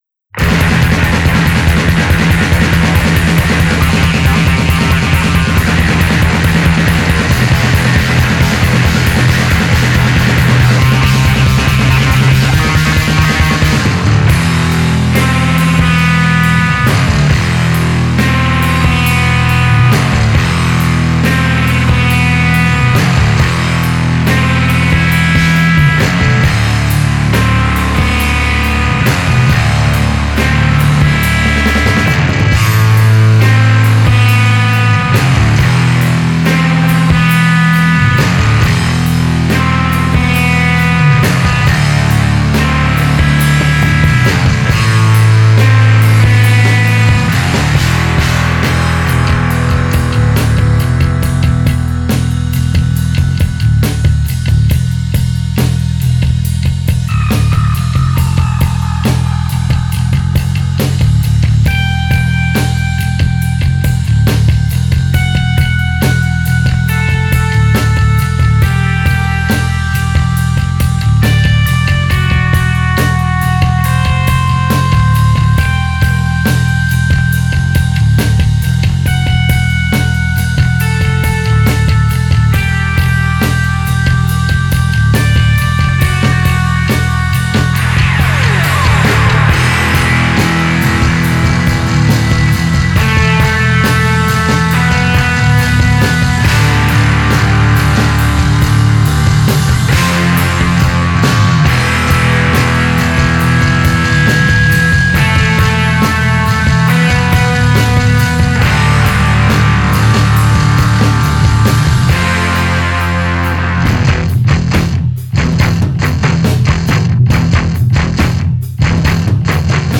le groupe compose un post rock/post metal stratosphérique.
sept pièces instrumentales sous forme de montagnes russes